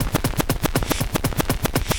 ClubMusic_transitionScratch.ogg